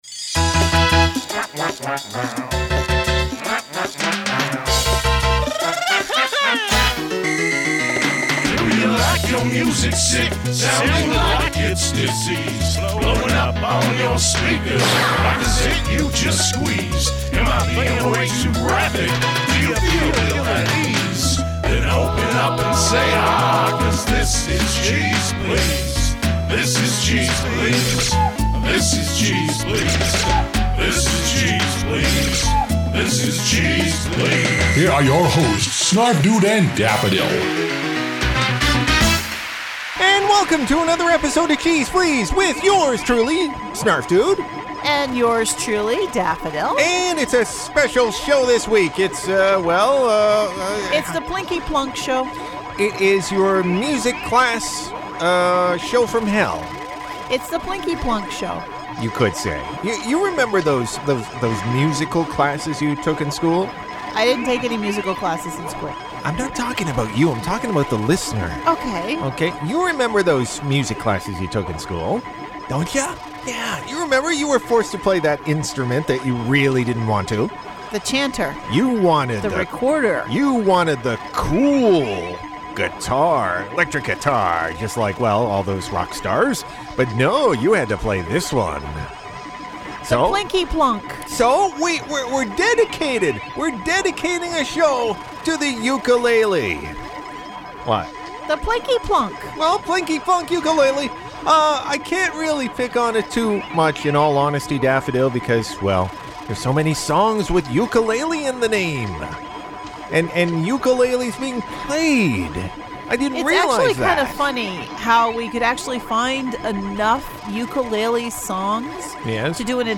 Our Ukelele Special